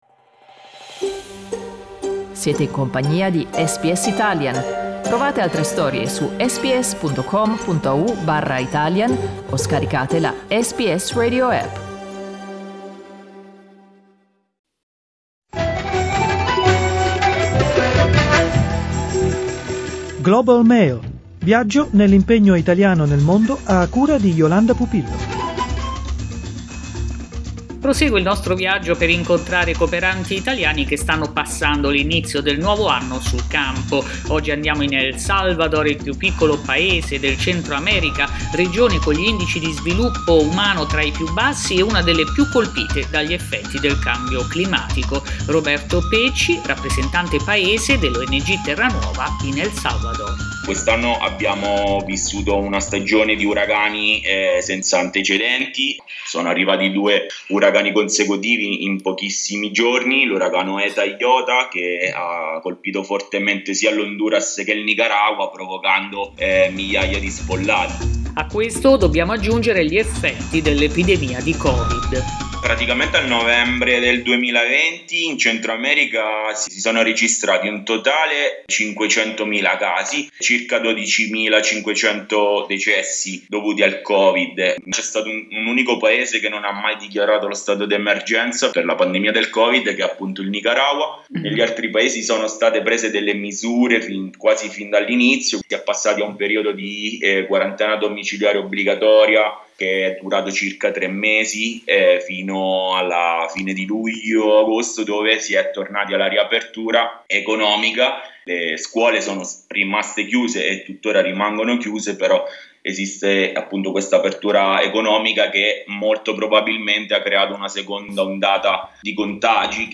Ascolta l'intervista: LISTEN TO L'ONG a sostegno della comunità LGBTIQ+ in El Salvador SBS Italian 07:53 Italian Le persone in Australia devono stare ad almeno 1,5 metri di distanza dagli altri.